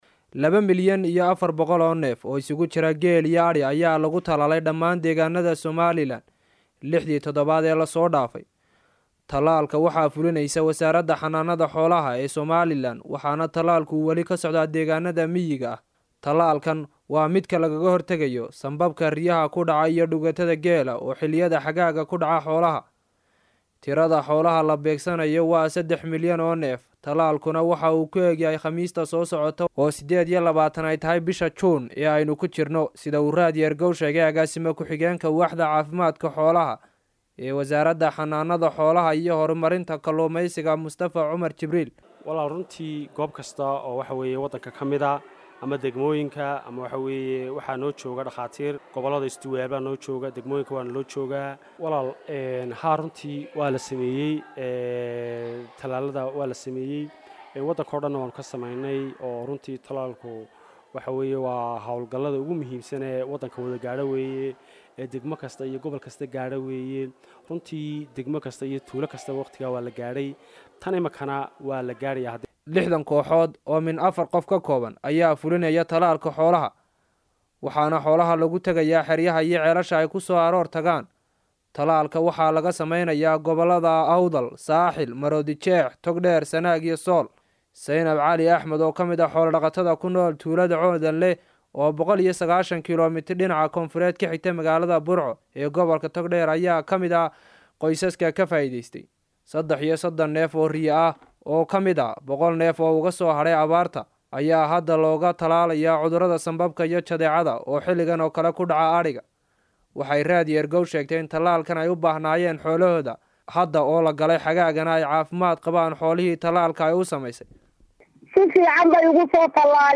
Warbixin-Talaalka-.mp3